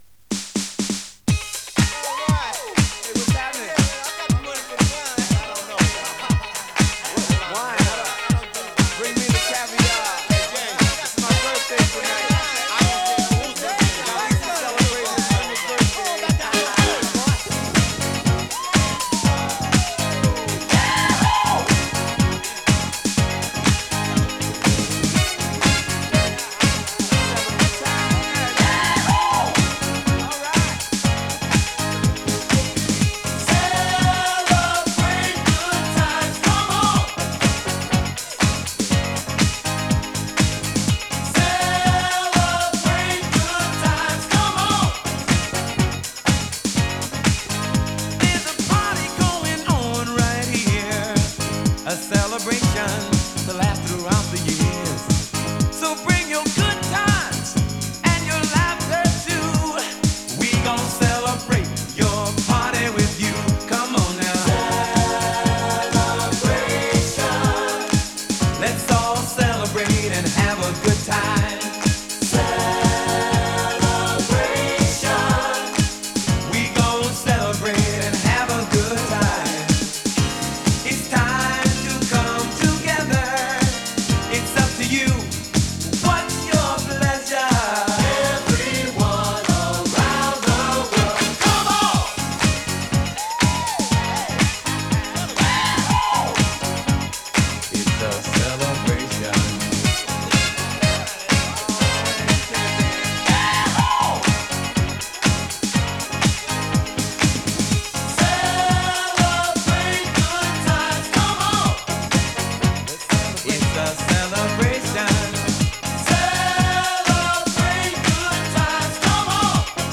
モダンソウル